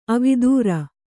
♪ avidūra